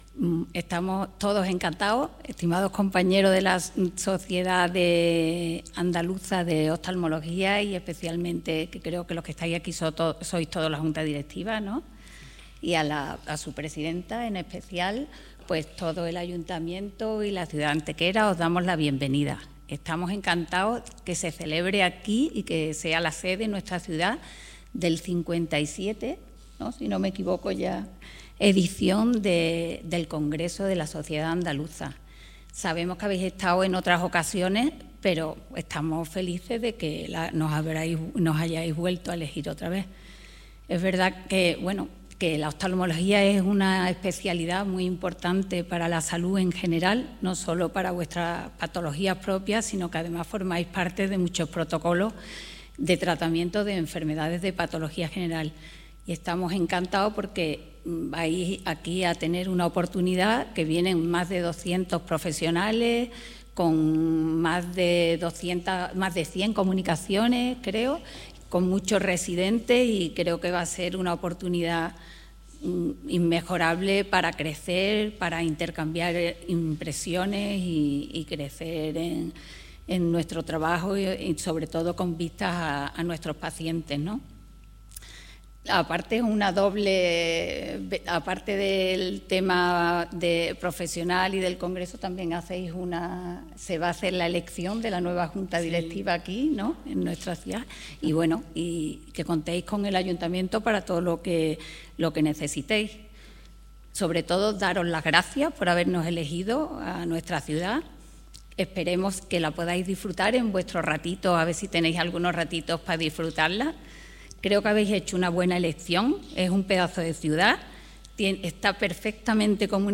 El Salón de Plenos del Ayuntamiento de Antequera ha acogido esta tarde el acto oficial de recepción a la junta directiva de la Sociedad Andaluza de Oftalmología, con motivo del desarrollo en la ciudad del LVII Congreso Anual de la entidad.
Cortes de voz